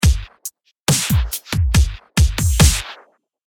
REVERBY环境鼓
描述：带有过多混响的现场鼓声
标签： 回声 混响鼓采样 混响
声道立体声